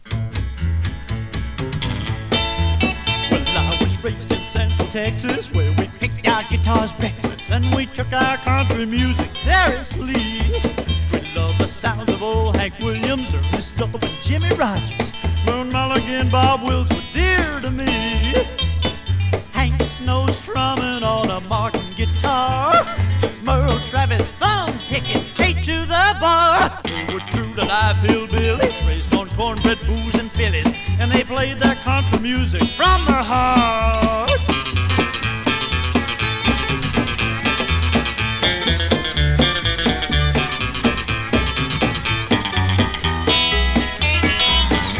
低予算、多重録音による音の質感も最高な大傑作。
Rock, Rockabilly　USA　12inchレコード　33rpm　Mono